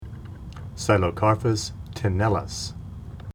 Pronunciation:
Psi-lo-cár-phus te-nél-lus